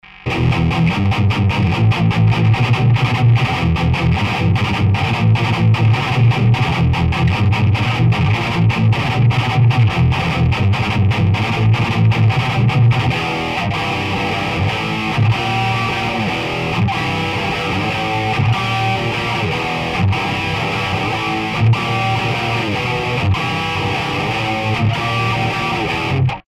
oimpulzovany preamp bez akychkolvek EQ uprav vyzera takto:
co uz je realnemu aparatu (preamp -> koniec ->box) ako ho doma pocujem velmi blizko